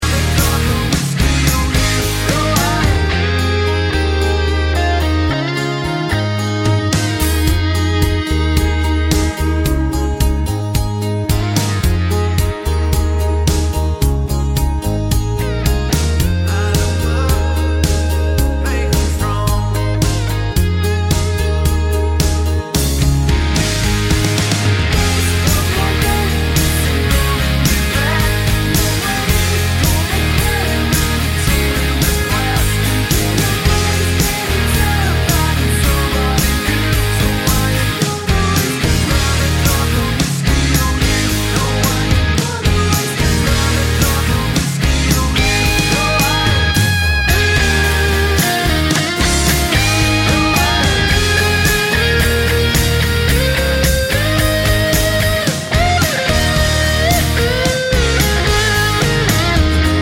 no Backing Vocals Country